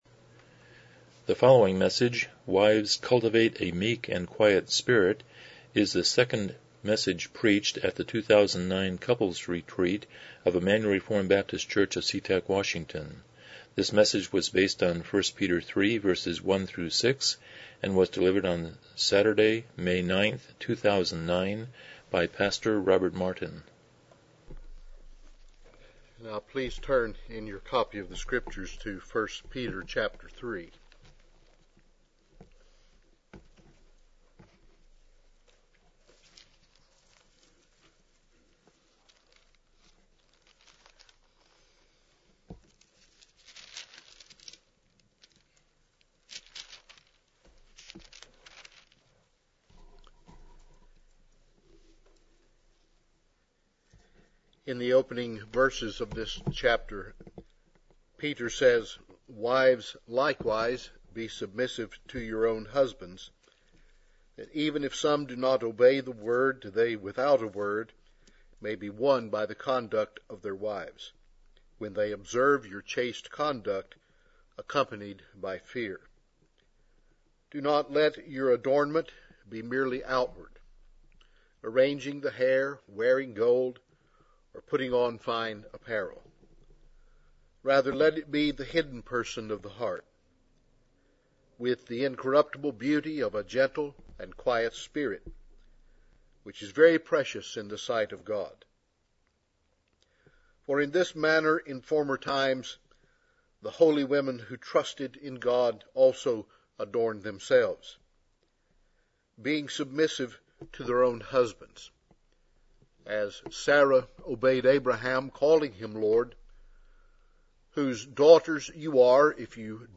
Passage: 1 Peter 3:1-6 Service Type: Special Event